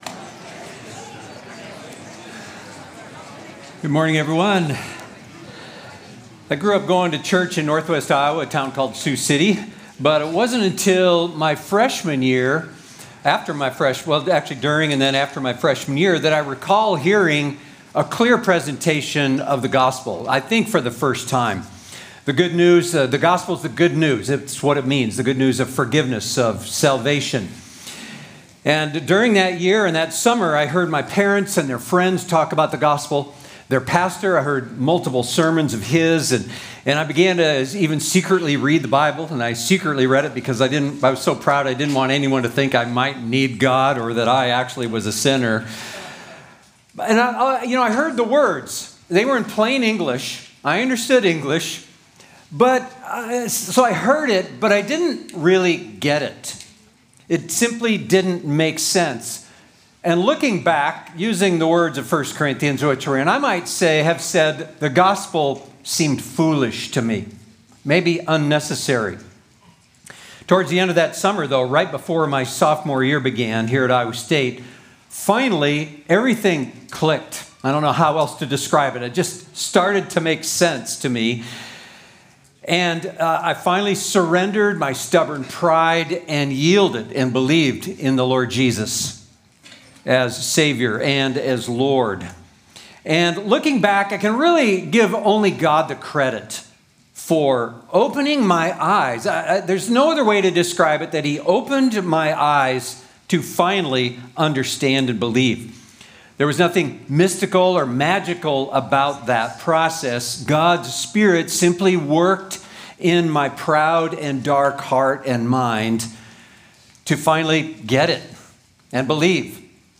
We are in a sermon series in the NT letter called, 1st Corinthians.